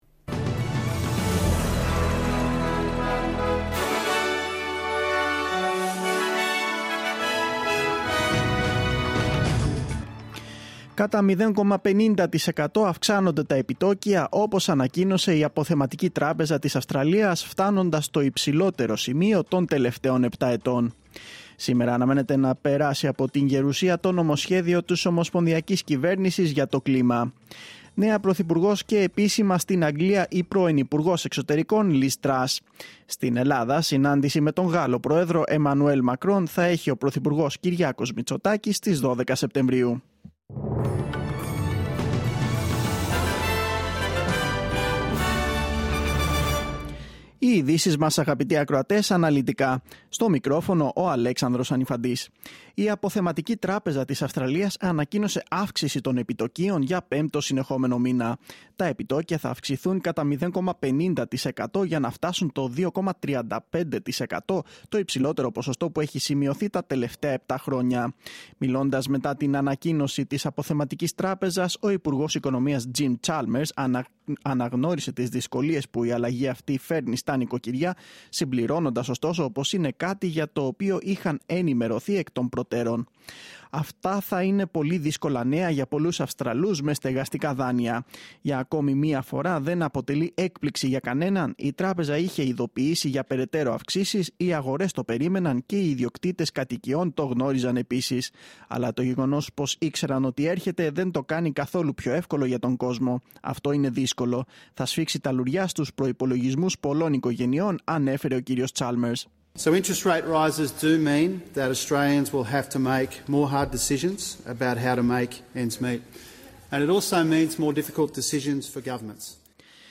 Δελτίο Ειδήσεων: Τρίτη 6-9-2022